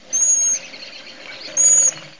Название свое они получили от звуков, которые издают при пении: сви-ри-ри.
kedrovij-ili-amerikanskij-sviristel-bombycilla-cedrorum.mp3